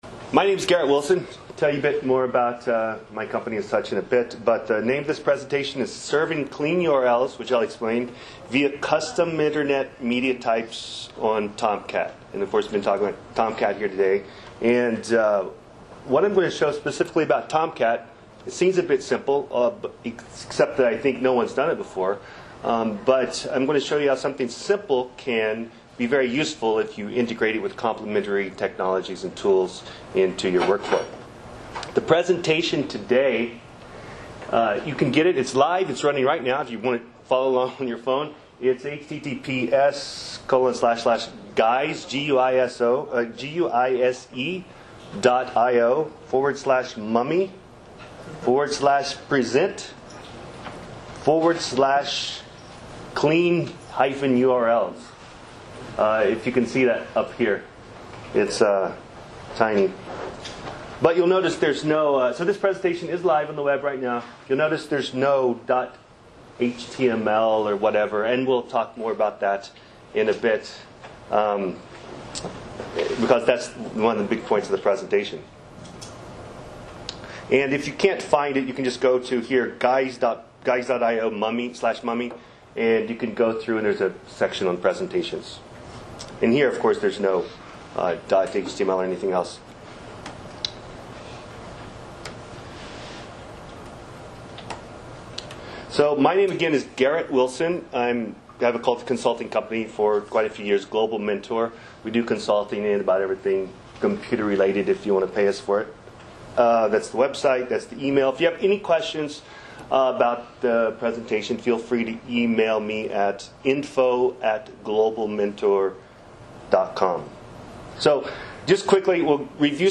This presentation shows how Tomcat has been extended to dynamically determine MIME type at runtime based on metadata stored in a parallel file tree or as sidecar files, and to serve the pages with clean resource names directly from Tomcat with no need for URL rewriting. A tutorial on the static site generator Guise Mummy is also included, illustrating how to generate a static site with clean resource names, host the site immediately using embedded Tomcat, and/or deploy the site to AWS S3 using bucket object metadata with a single command.